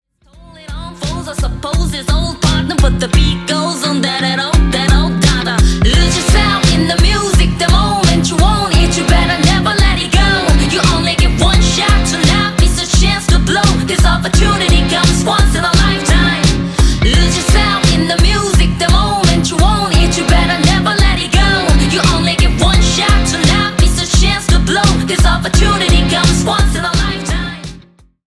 Рэп и Хип Хоп
кавер